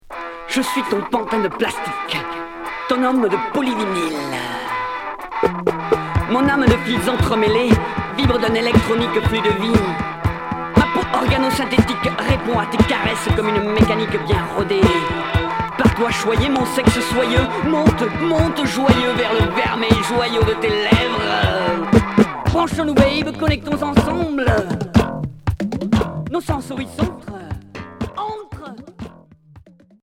Rock décalé